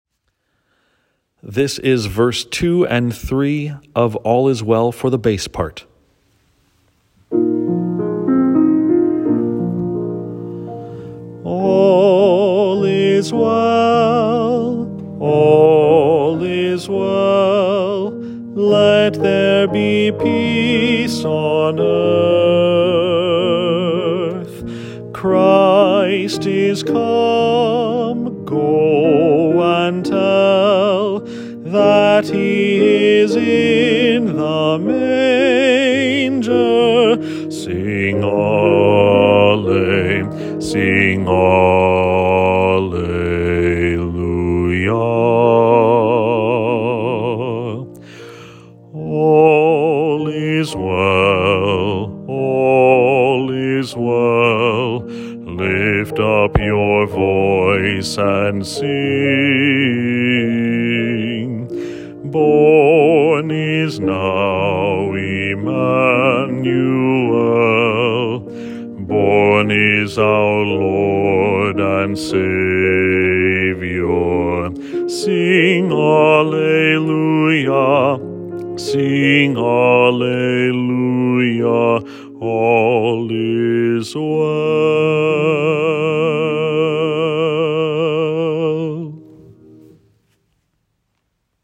For the bass recording, starting at verse 2, click here!
All_Is_Well_Bass_.m4a